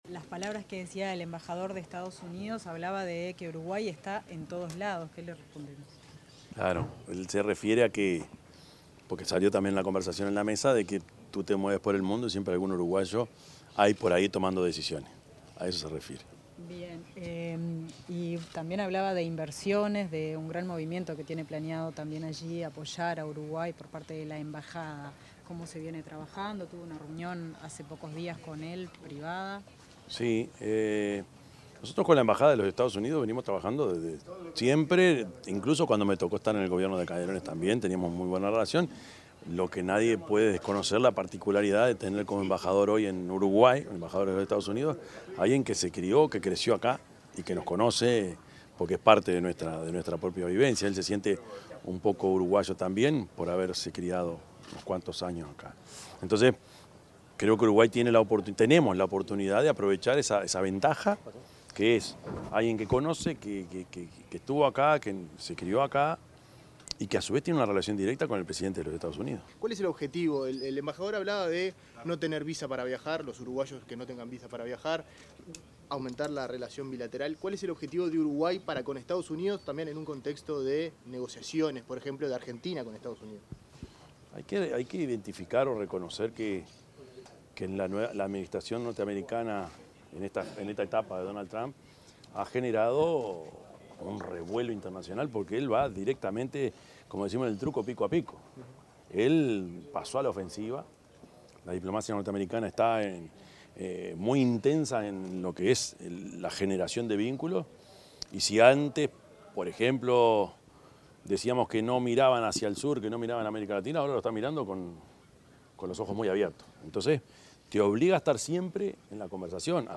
Declaraciones del presidente de la República, Yamandú Orsi
Declaraciones del presidente de la República, Yamandú Orsi 24/11/2025 Compartir Facebook X Copiar enlace WhatsApp LinkedIn Tras participar de un almuerzo con el embajador de Estados Unidos en Uruguay, Lou Rinaldi, organizado por la Cámara de Comercio Uruguay - Estados Unidos, el presidente de la República, Yamandú Orsi, brindó declaraciones a la prensa.